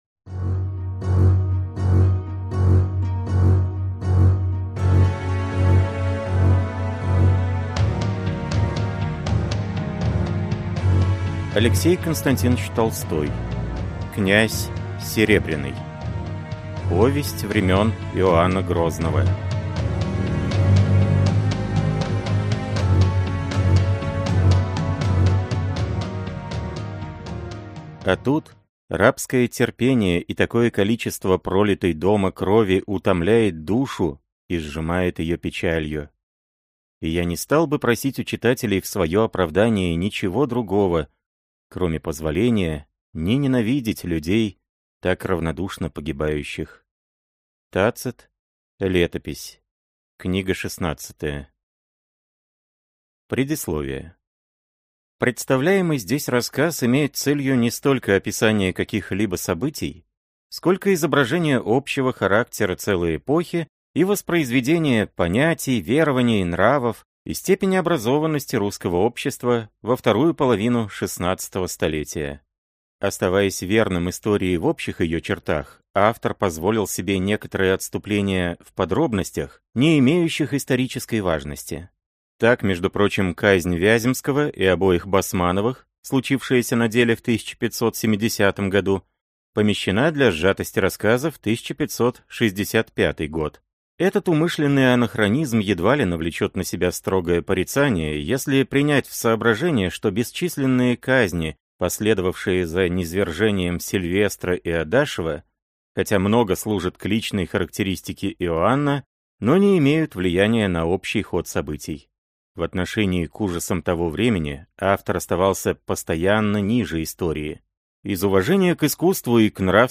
Аудиокнига Князь Серебряный | Библиотека аудиокниг